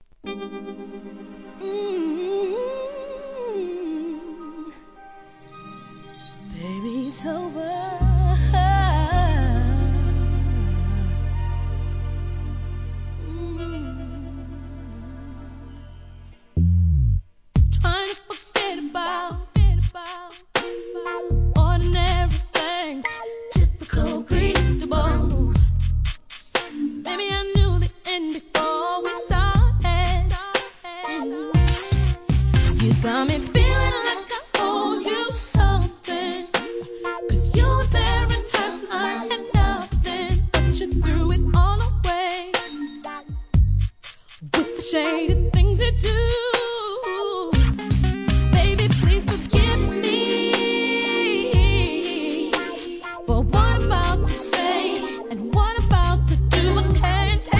HIP HOP/R&B
1998年のSLOWナンバー!